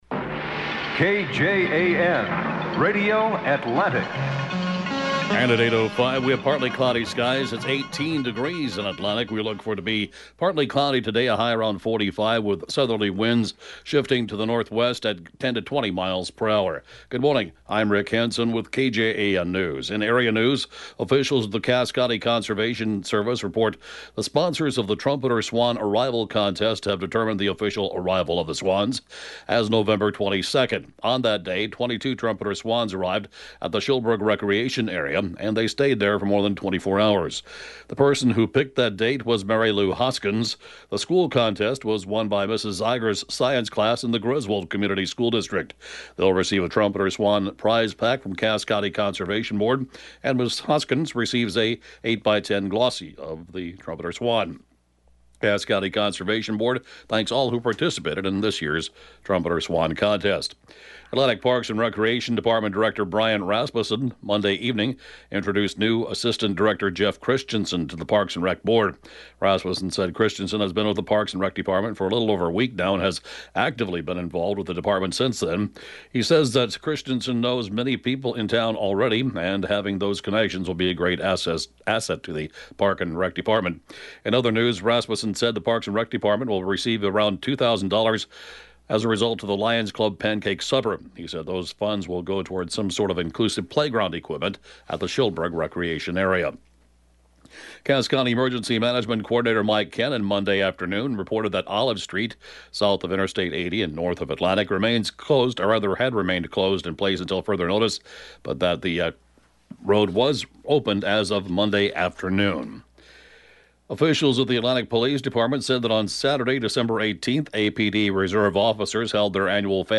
(Podcast) KJAN area News, 12/21/21